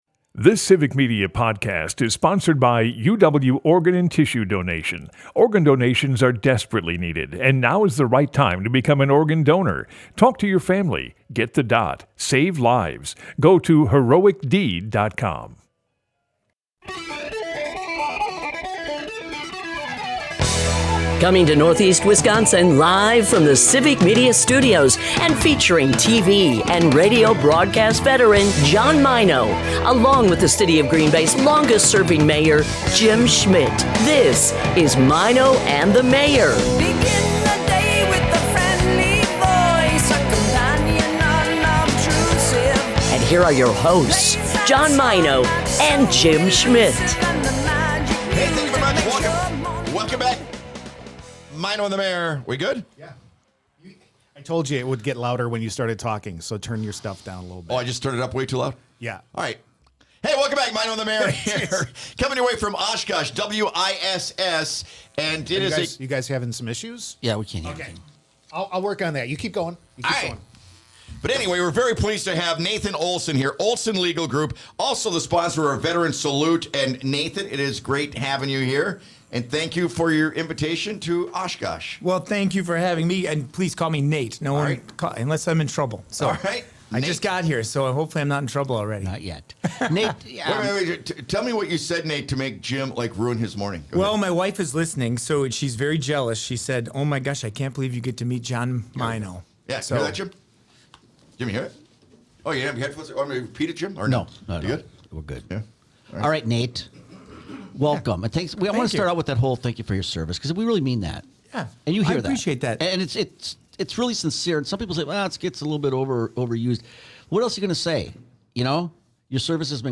Broadcasts live 6 - 9am in Oshkosh, Appleton, Green Bay and surrounding areas.